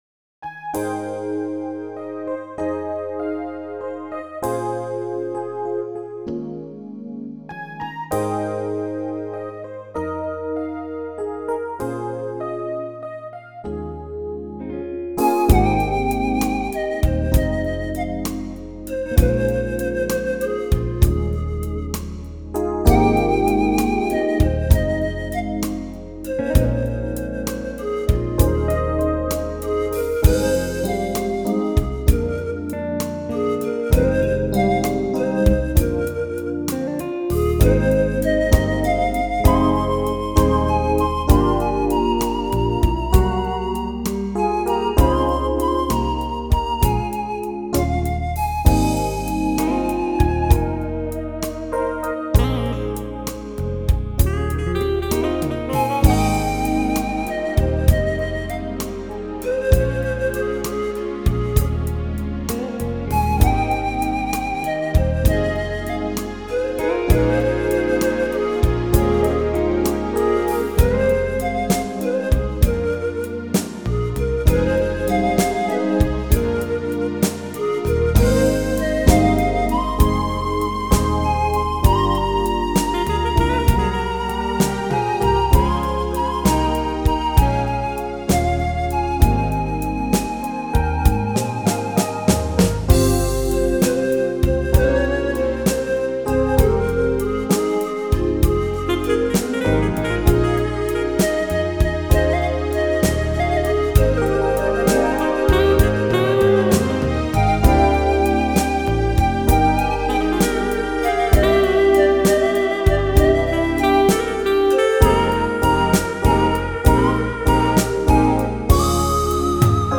thI6THU4E6  Download Instrumental